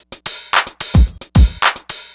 TSNRG2 Breakbeat 012.wav